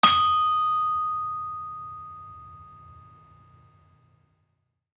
Saron-5-D#5-f.wav